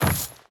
Wood Chain Land.ogg